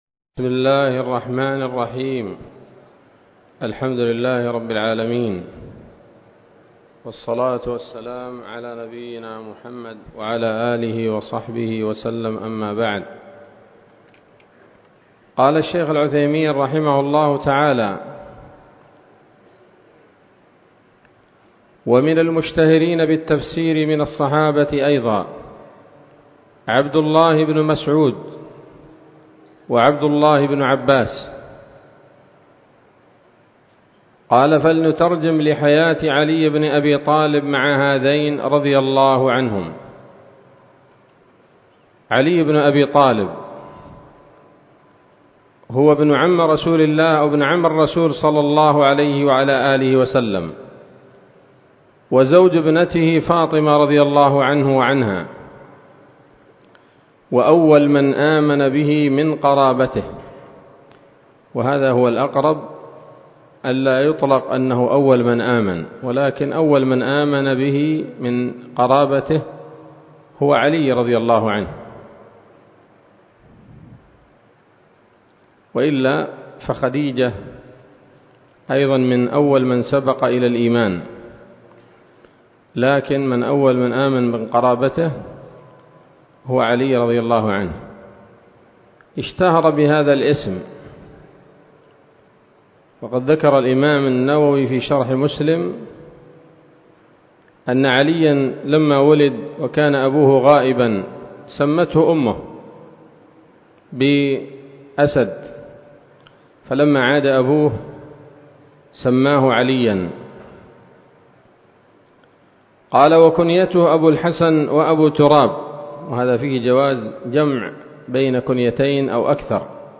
الدرس الرابع والعشرون من أصول في التفسير للعلامة العثيمين رحمه الله تعالى 1446 هـ